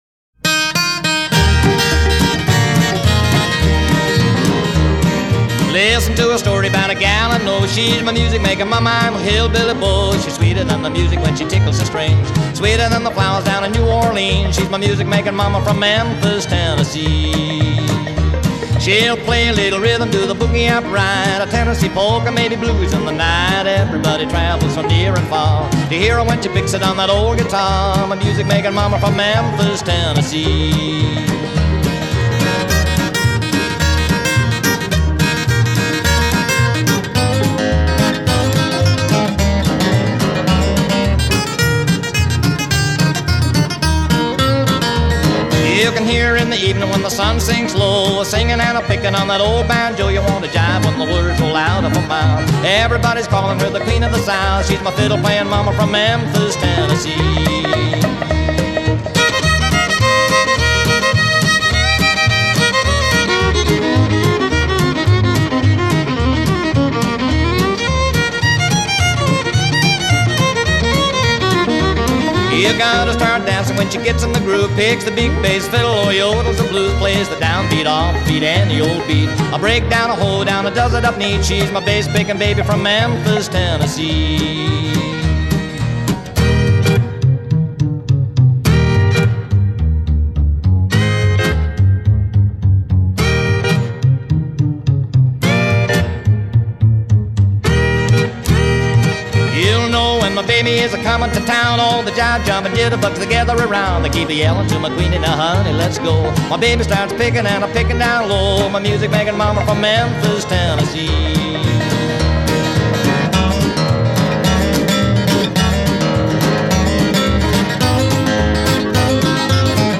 Honky-Tonk Rockabilly